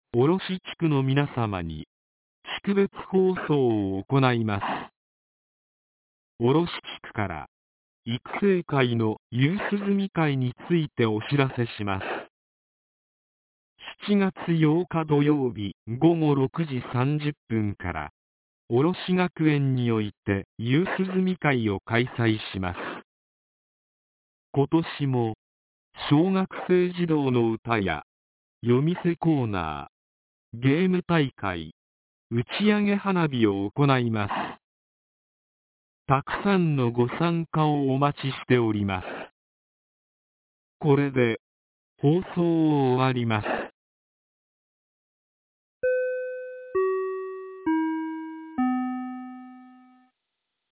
■防災行政無線情報■ | 三重県御浜町メール配信サービス